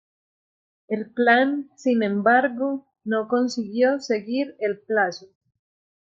Read more (masculine) plan (masculine) scheme (systematic plan of future action) (masculine) intention (colloquial, masculine) mood Frequency A1 Pronounced as (IPA) /ˈplan/ Etymology From plano.